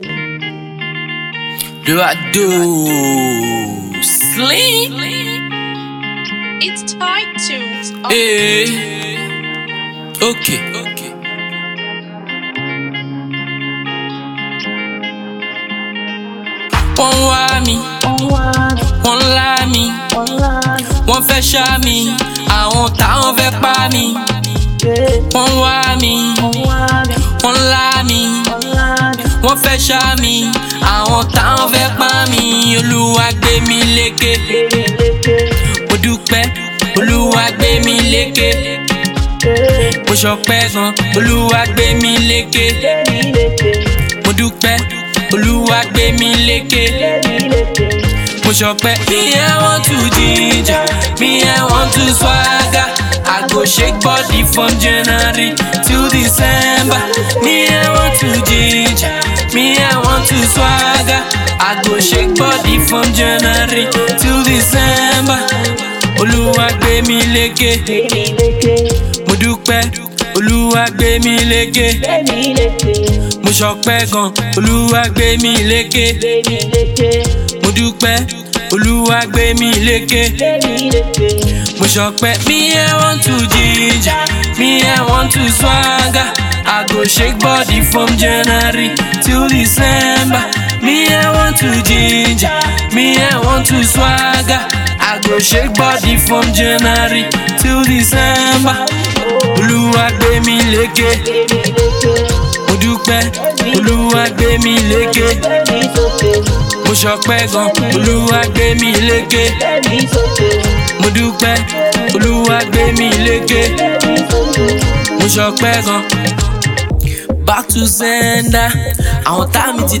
mad street bangers
street vibe